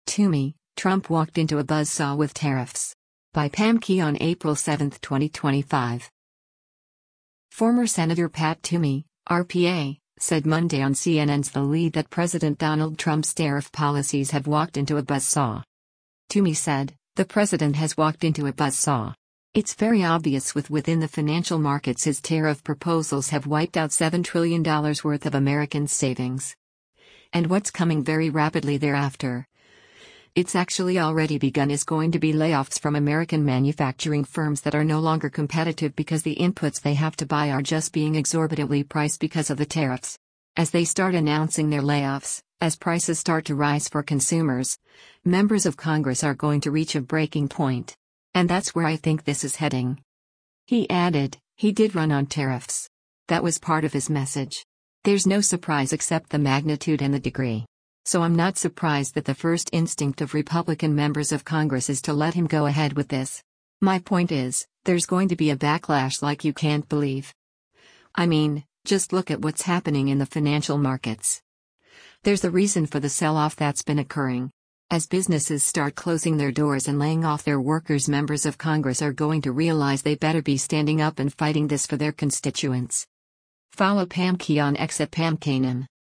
Former Sen. Pat Toomey (R-PA) said Monday on CNN’s “The Lead” that President Donald Trump’s tariff policies have “walked into a buzzsaw.”